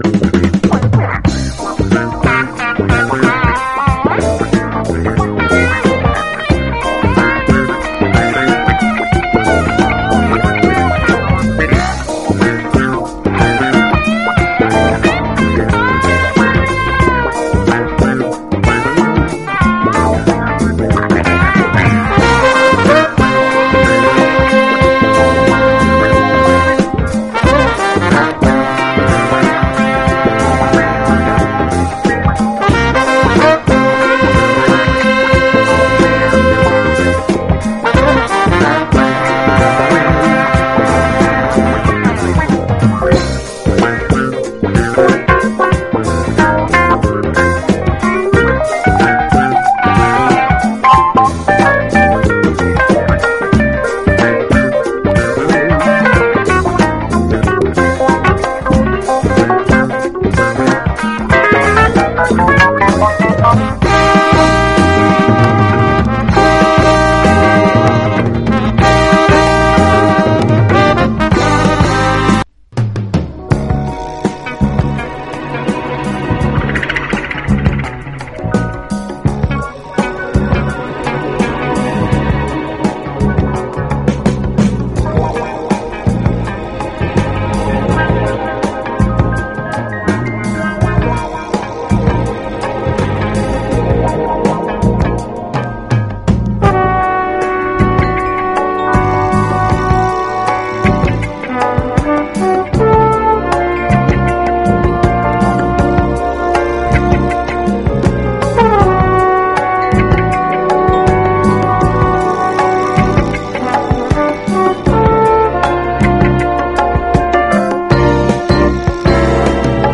JAZZ / DANCEFLOOR / GUITAR / JAZZ FUNK